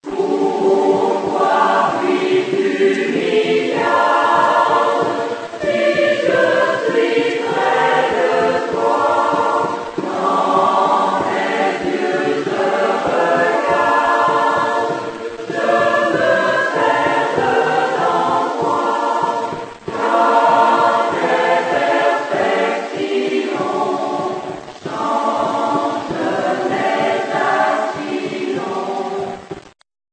75 hommes et femmes originaires de la côte Nord (de Tadoussac à Forestville) ont choisi de se rencontrer régulièrement pour fusionner leurs voix dans un choeur vibrant. La chorale baptisée "Les gens de mon pays" avec les encouragements de Gilles Vigneault, était, dès la 1ère année de son existence, en mesure de partager avec le public le bonheur de chanter Plamondon, Vigneault et des mélodies du moyen âge.
chorale.rm